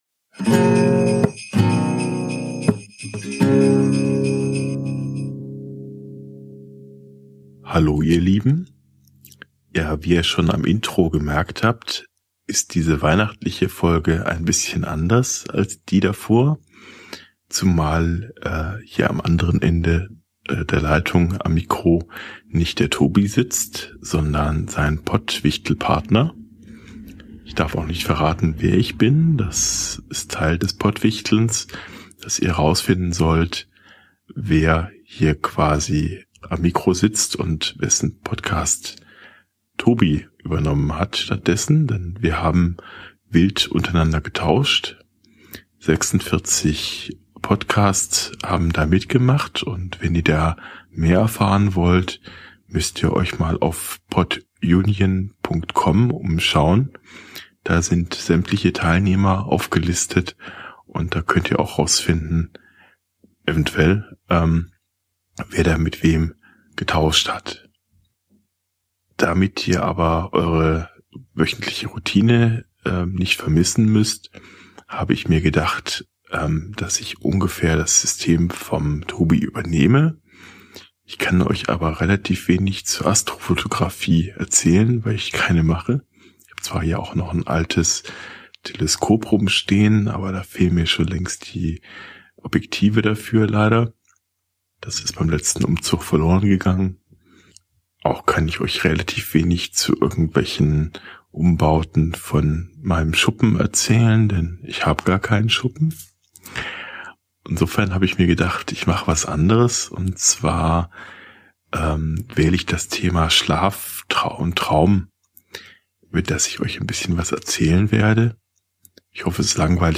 Monologe zum Entspannen